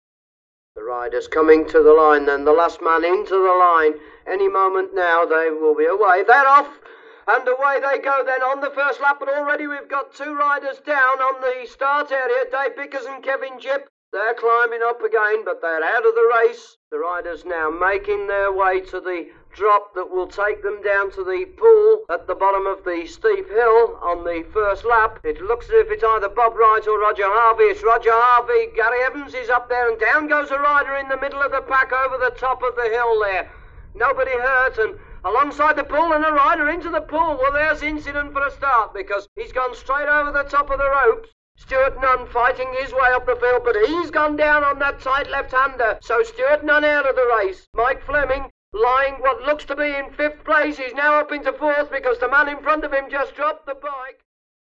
Sports Announcer | Sneak On The Lot
Motorcycle; Race Track P.a.; Scramble Commentary Slight Pa.